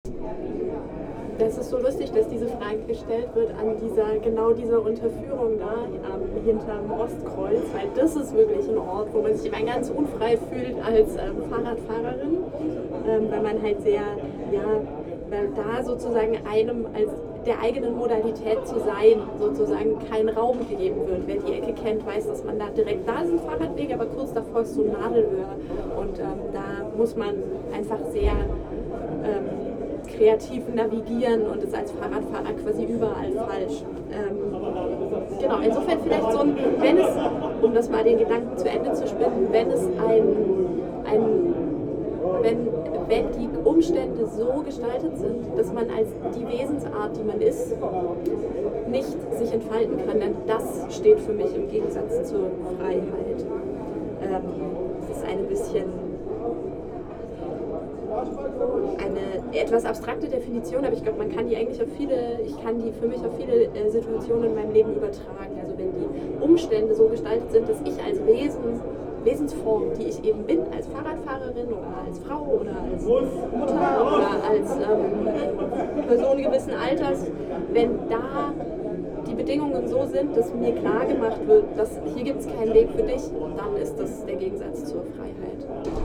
Standort der Erzählbox:
FONA Forum @ Berlin, Futurum